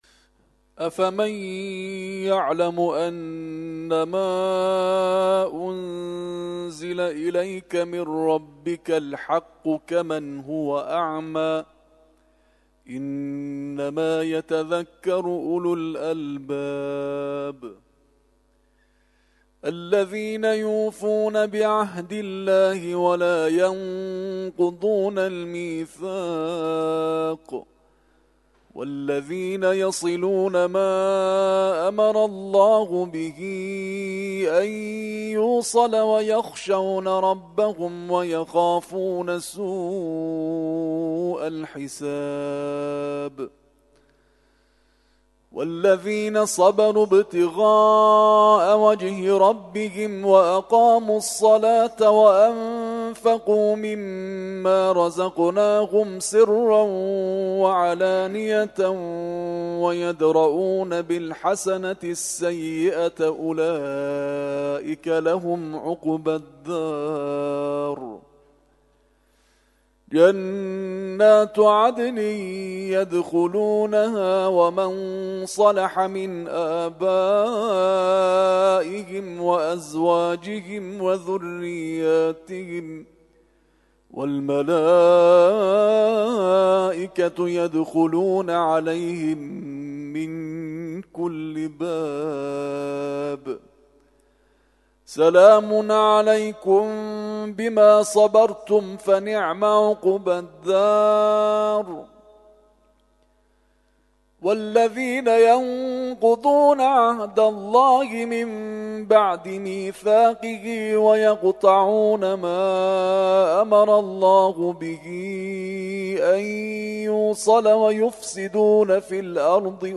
ترتیل خوانی جزء ۱۳ قرآن کریم - سال ۱۳۹۹